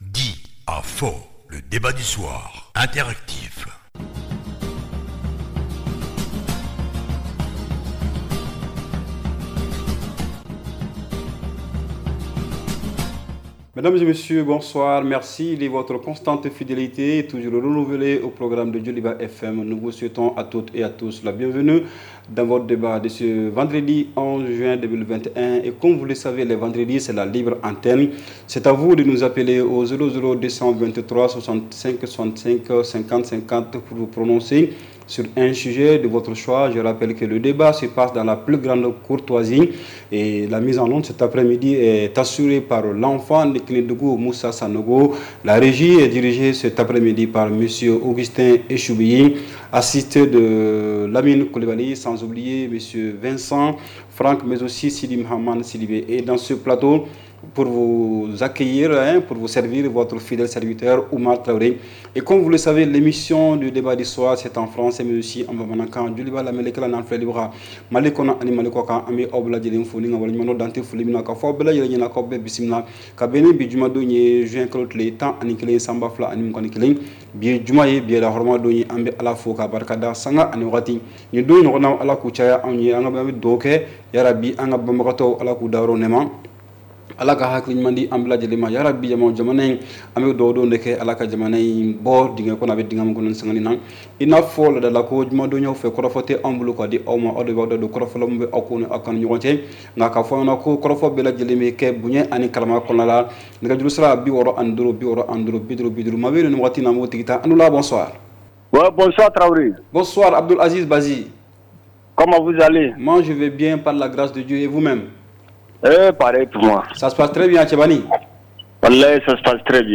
REPLAY 10/06 – « DIS ! » Le Débat Interactif du Soir
Appelle-nous et donne ton point de vue sur une question d’actualité (politique, économique, culturelle, religieuse, etc.). Pas de sujets tabous : arguments, contre arguments !